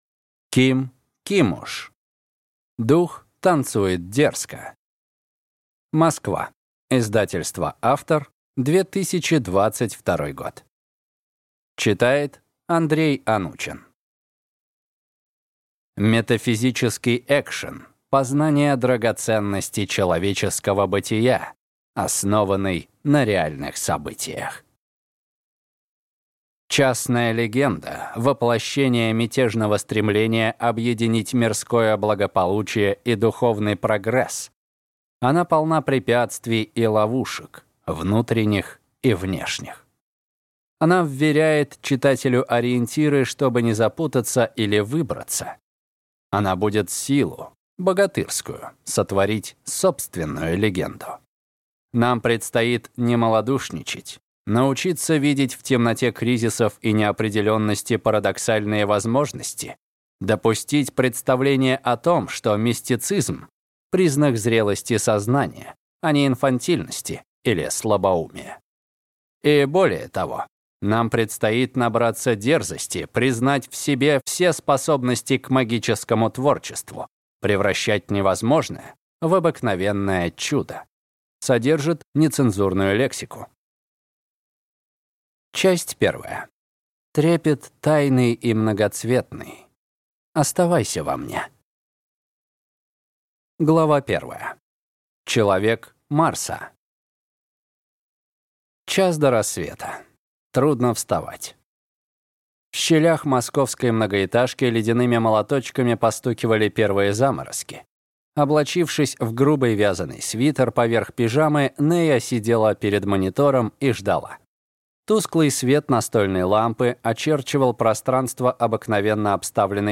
Аудиокнига Дух танцует дерзко | Библиотека аудиокниг